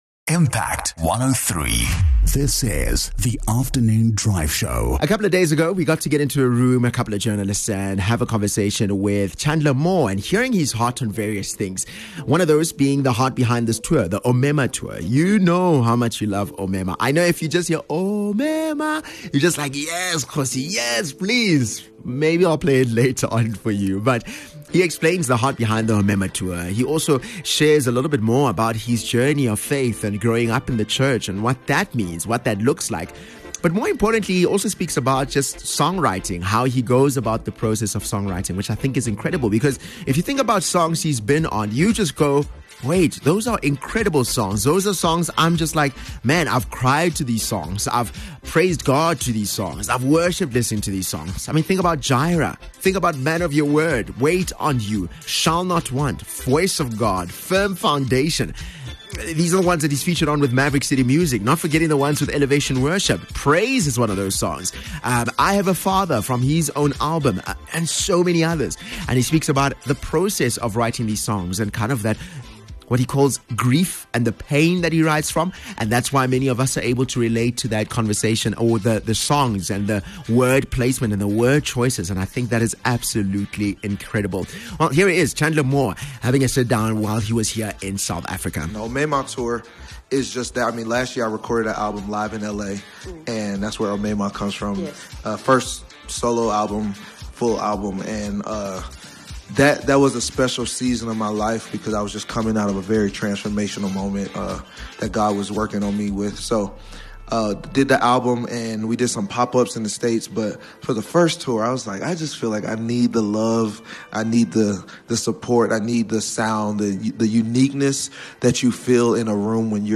interview with Chandler Moore